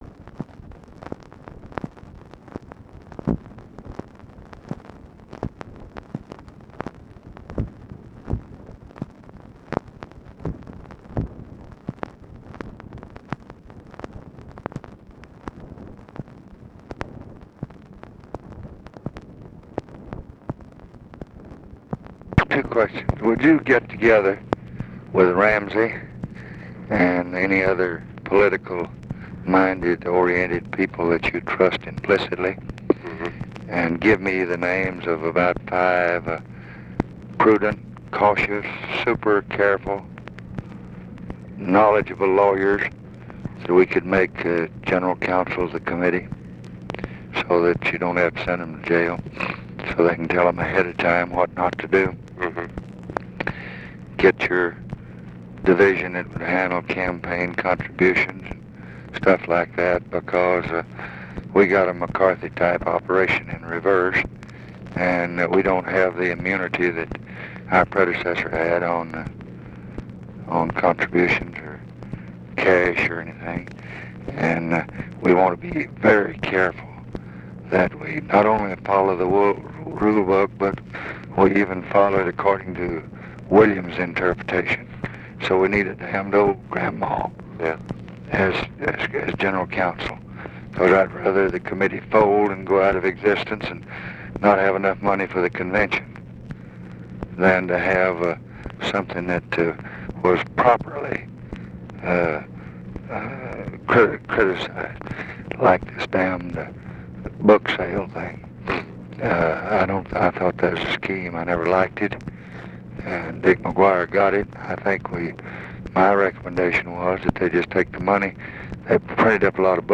Conversation with NICHOLAS KATZENBACH, April 29, 1966
Secret White House Tapes